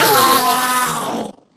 mob / endermen / death / hit.ogg